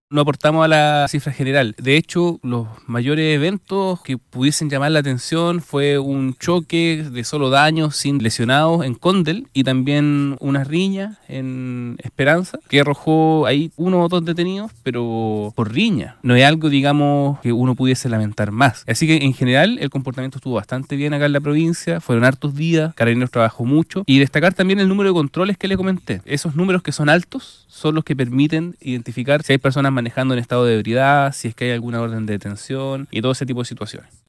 El delegado, detalló dos situaciones,  que fueron las excepciones en el marco de los festejos en Quillota y La Calera, las mínimas respecto a otros años, y considerando que este año se contó con 4 días de festejos dieciocheros: